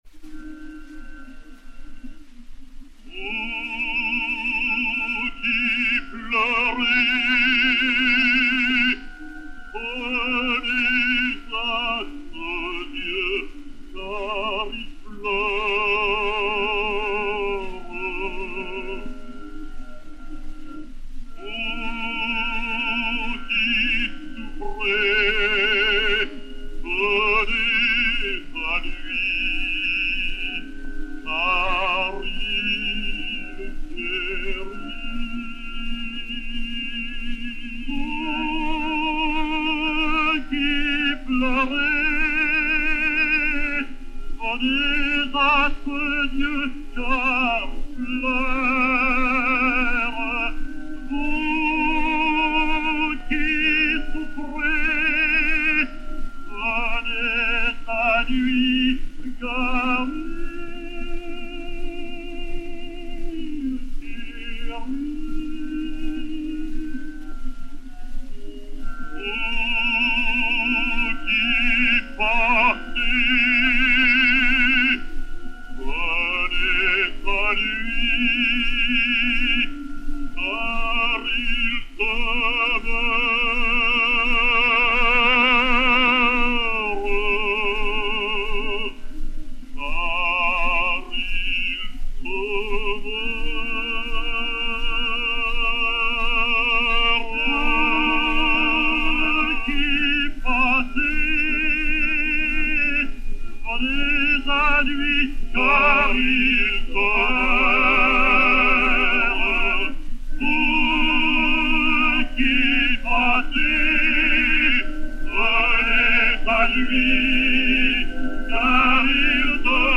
Chant religieux à deux voix